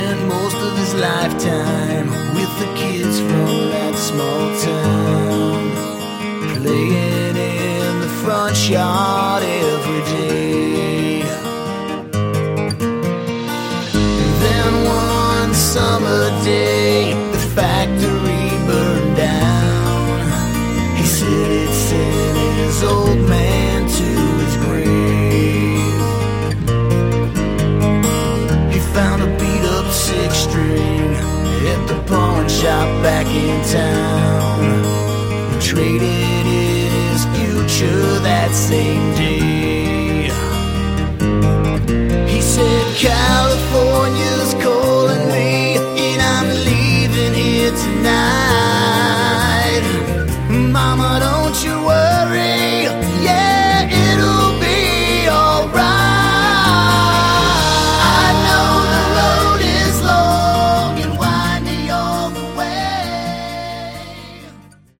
Category: Melodic Hard Rock
Lead Vocals, Bass, 12 String Guitar
Drums, Percussion, Backing Vocals
Guitars, Background Vocals